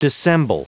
Prononciation du mot dissemble en anglais (fichier audio)
Prononciation du mot : dissemble